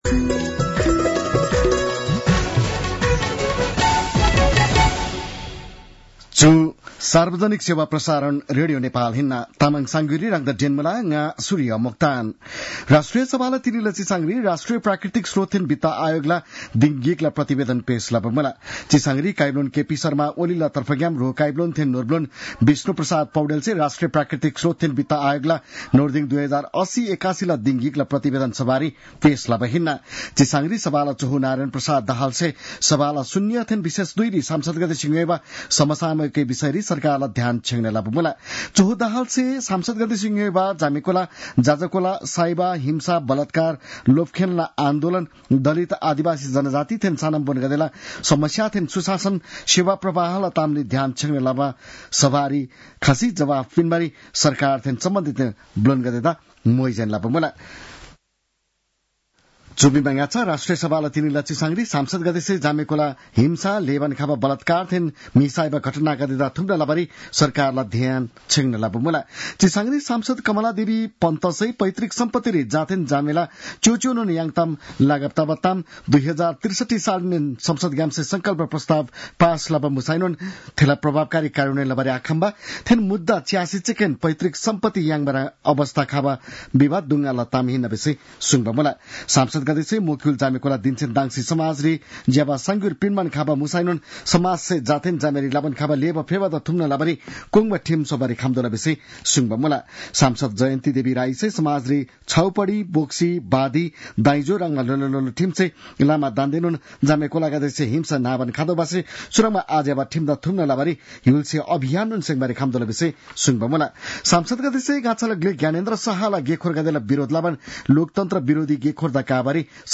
तामाङ भाषाको समाचार : २६ फागुन , २०८१